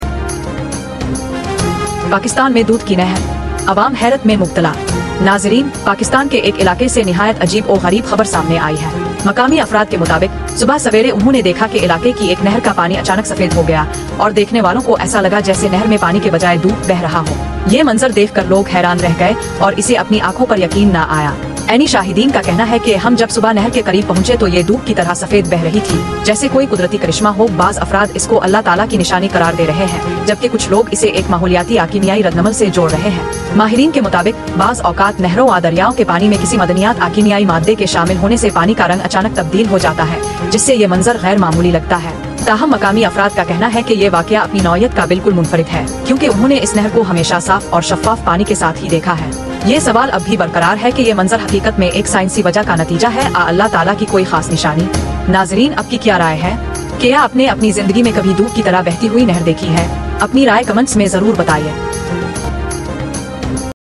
Geo News Headlines Today Sound Effects Free Download